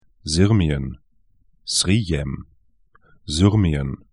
Aussprache
'zɪrmĭən
'zʏrmĭən   Srijem